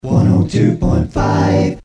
Station Jingle Package